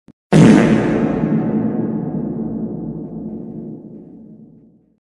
Звуки пердежа
Одиночный пук с отзвуком эха